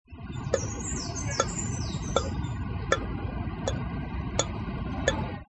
描述：este audio hace parte del foley de“the Elephant's dream”
Tag: 步行 脚步 金属 步骤